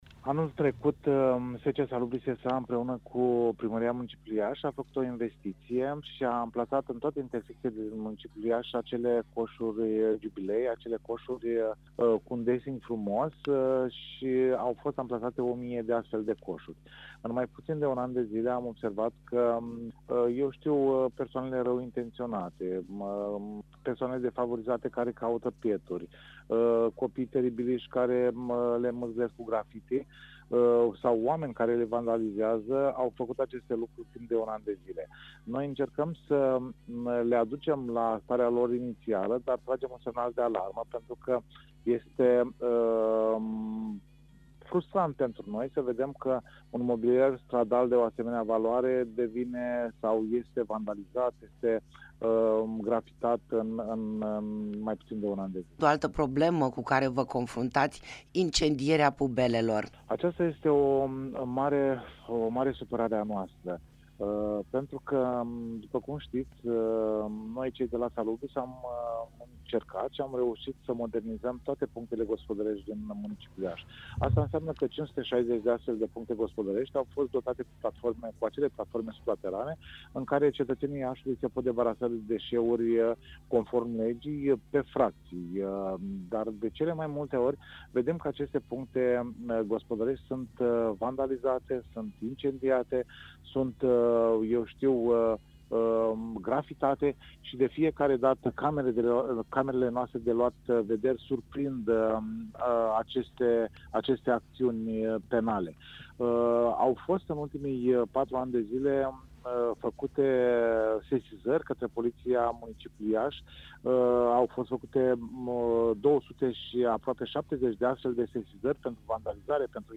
Interviu-pubele-incendiate.mp3